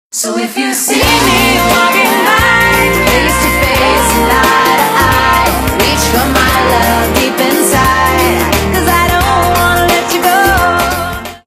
AlertFile310 - Communicate.m4a